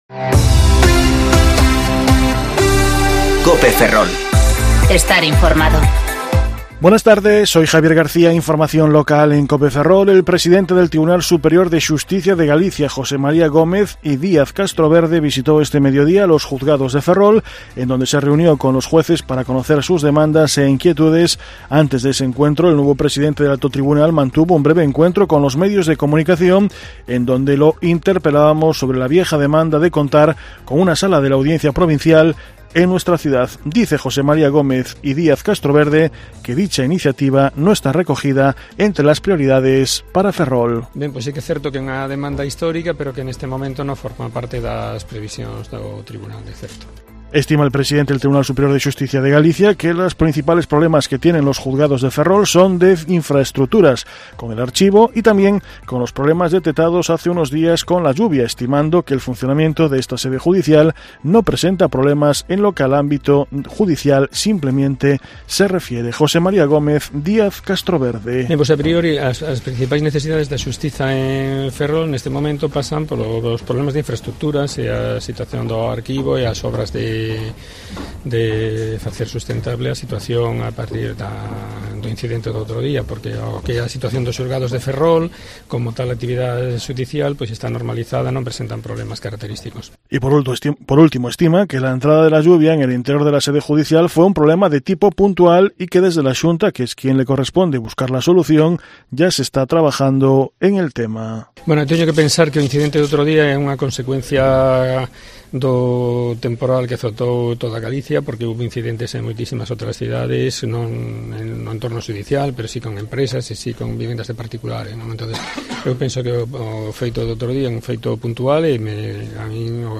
Informativo Mediodía COPE Ferrol 21/01/2020 (De 14,20 a a 14,30 horas)